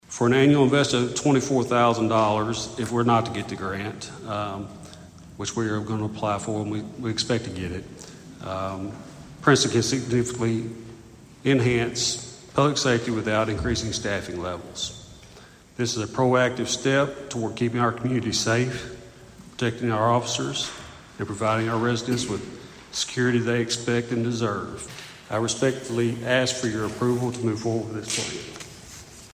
At Monday night’s meeting, the Princeton City Council approved applying for a grant to install automated license plate reader cameras at key city entry points.